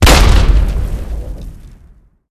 explosionSmallNear2.ogg